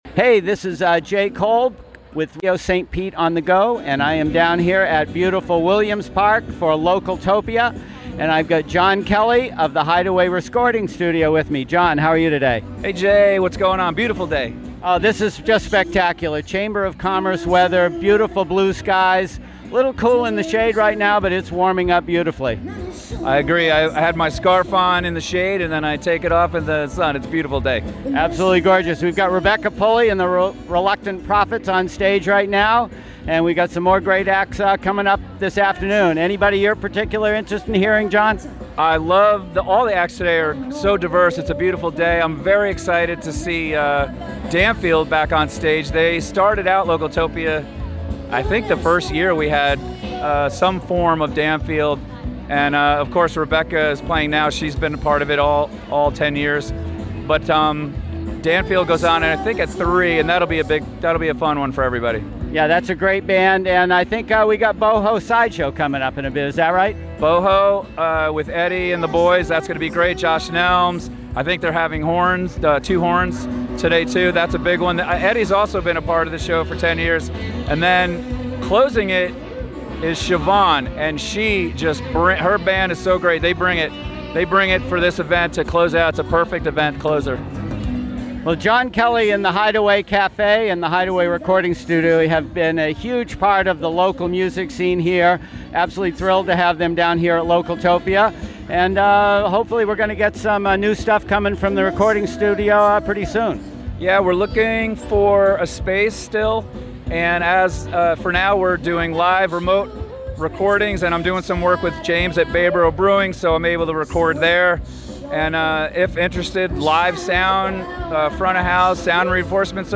"Localtopia 2023," 2-18-23; Live from Williams Park. Portions of our live coverage